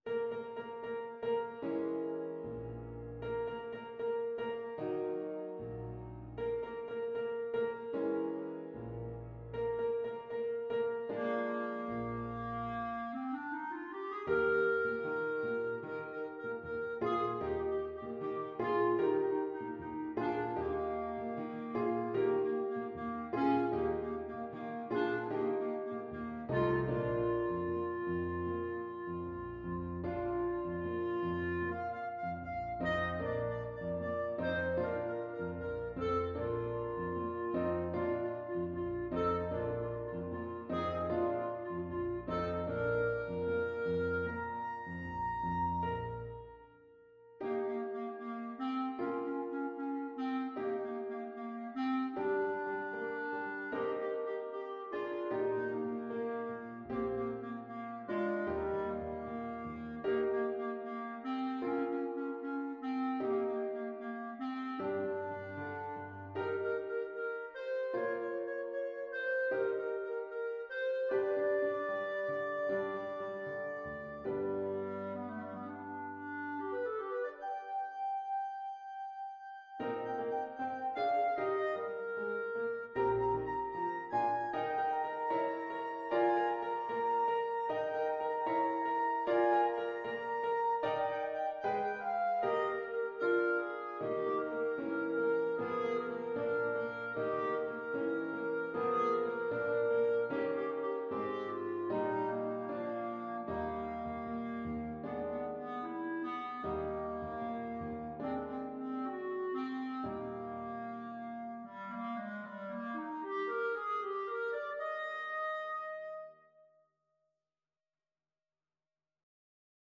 Clarinet
2/4 (View more 2/4 Music)
Eb major (Sounding Pitch) F major (Clarinet in Bb) (View more Eb major Music for Clarinet )
=76 Allegretto lusinghiero =104
Classical (View more Classical Clarinet Music)